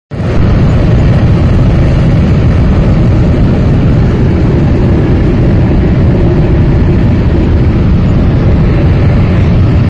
Download Jet Engine sound effect for free.
Jet Engine